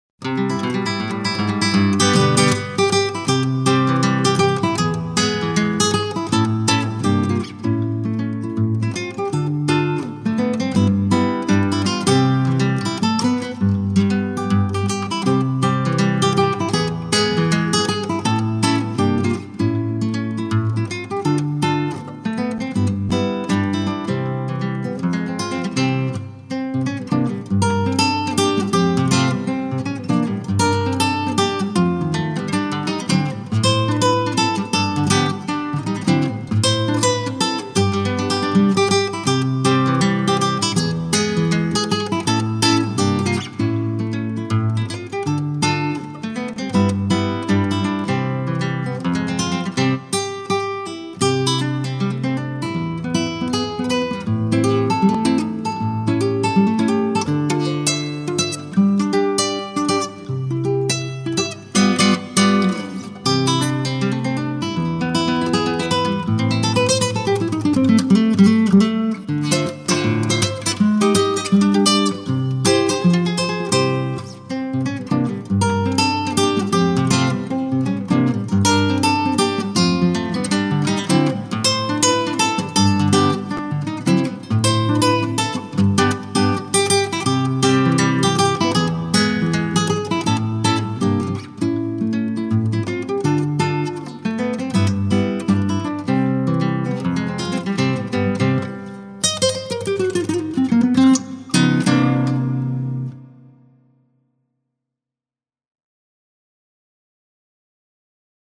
Гитарные пьесы